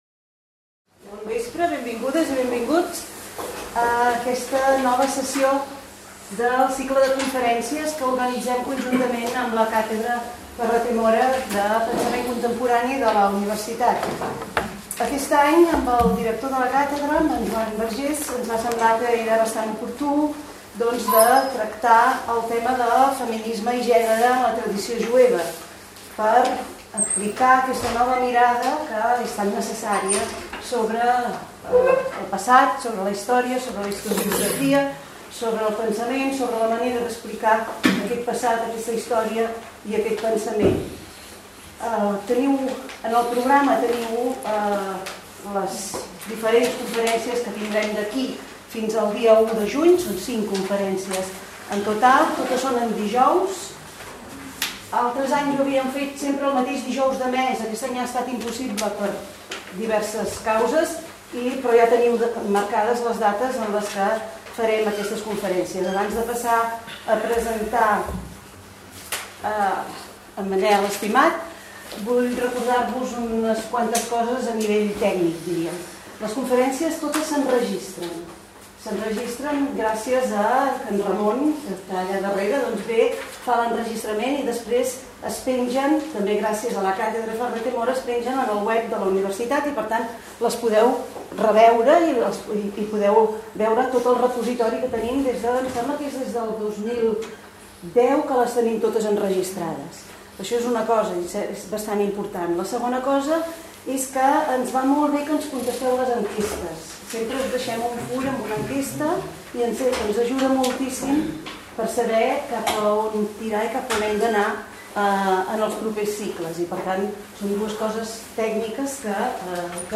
Conferència